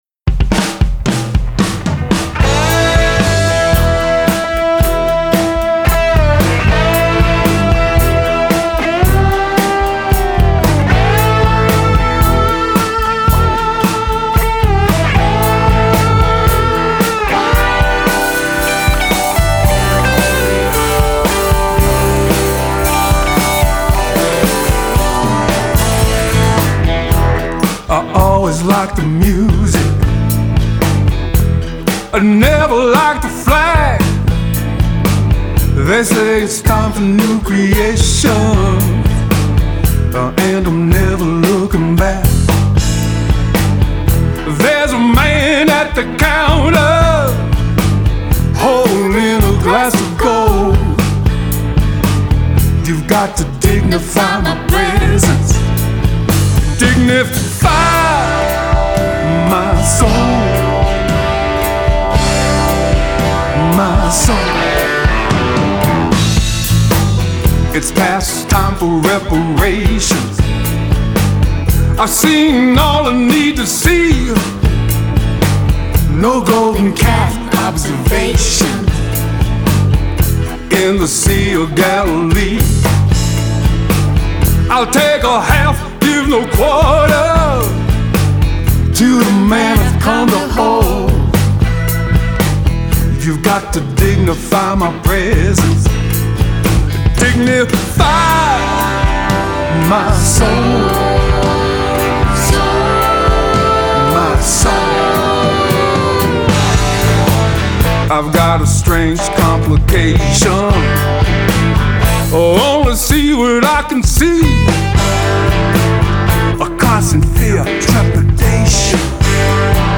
Genre : Rock, Blues